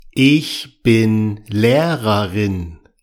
อิ(คช)-บิน-เล-เรอร์-ริน